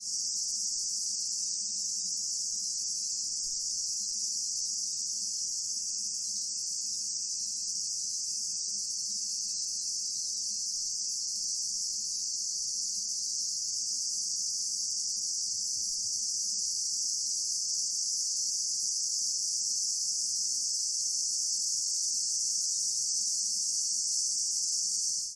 描述：我在网上寻找蝉的声音，找不到一个听起来像这里的人...但是我很幸运，最终能够得到一个漂亮，干净的录音！使用Sony IC Recorder记录出我的房子。在FL Studio中处理以消除噪音。
Tag: 性质 佛罗里达州 夏天 昆虫